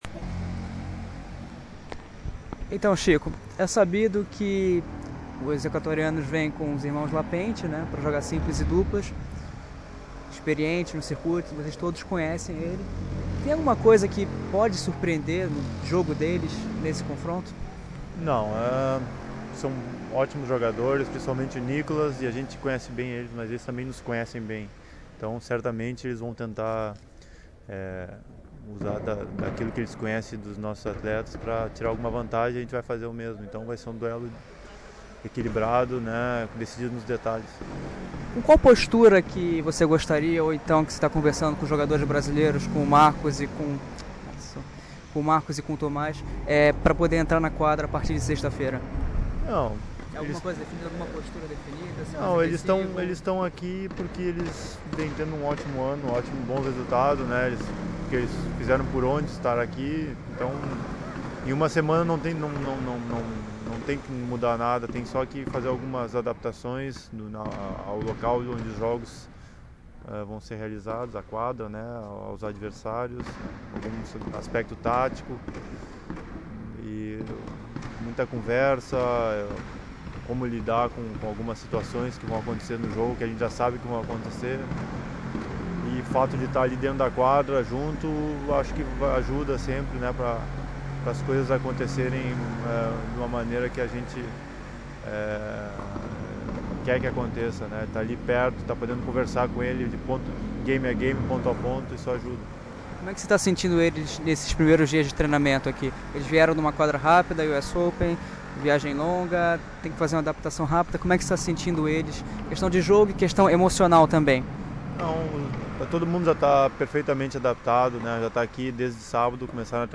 Entrevista - Tenis News